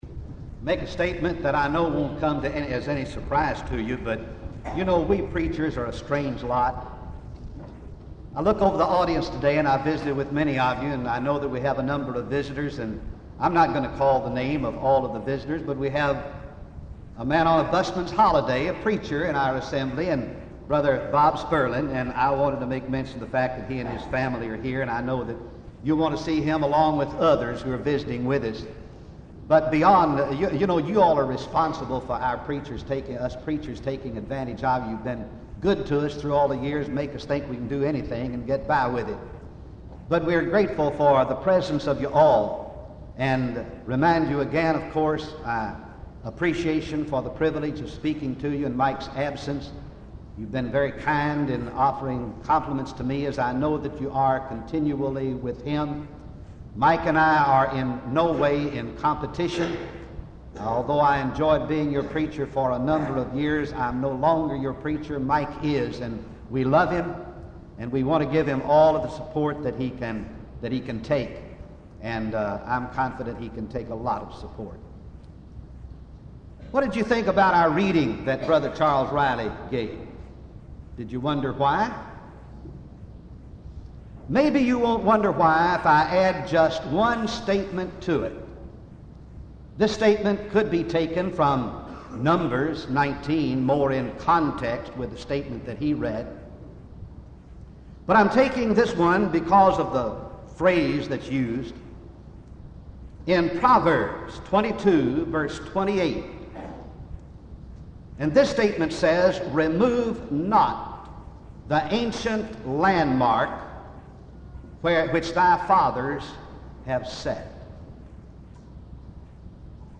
sermon
for Sunday evening worship